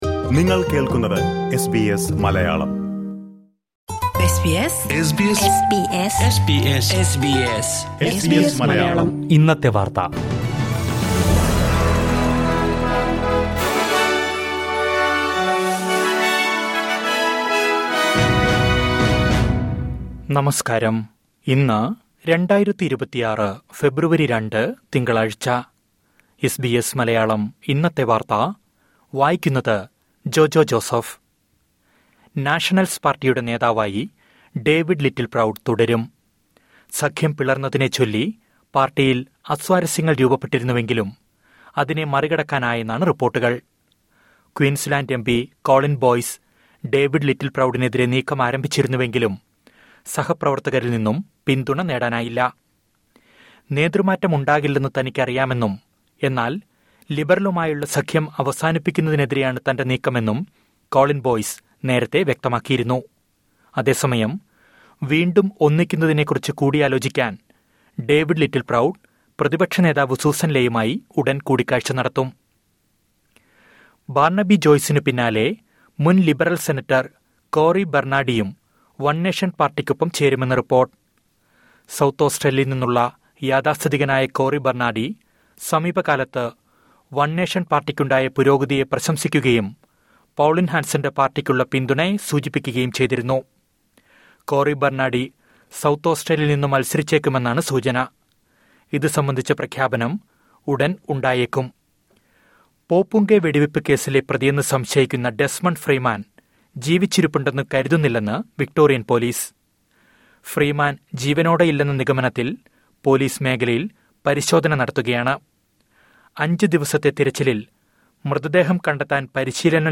2026 ഫെബ്രുവരി രണ്ടിലെ ഓസ്ട്രേലിയയിലെ ഏറ്റവും പ്രധാന വാർത്തകൾ കേൾക്കാം...